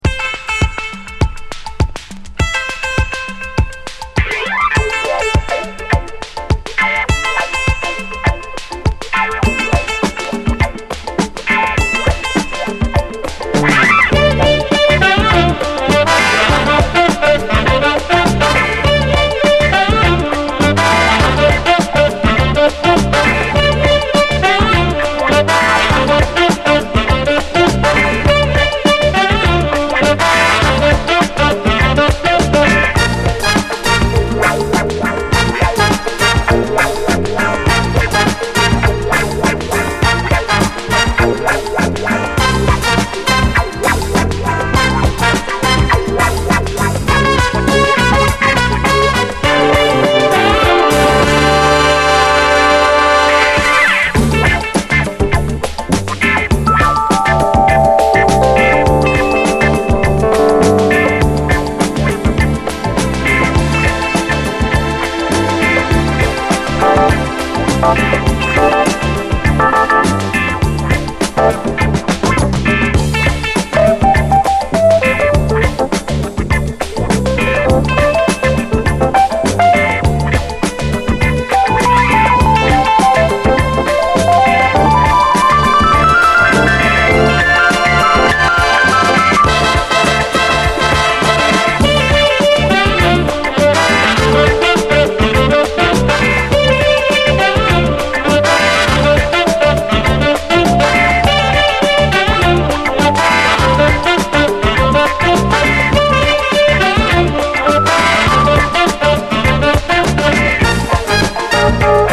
華やいだ週末感溢れるロマンティック・ダンサー
アーバンな90’Sモダン・ソウル